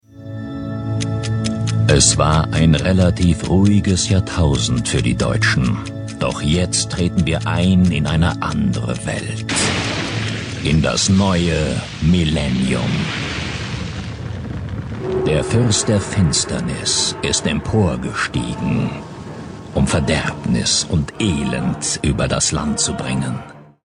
Prägnante tiefe Stimme mit großer Variationsbreite
Sprechprobe: Sonstiges (Muttersprache):
Great deep German voice